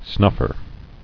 [snuff·er]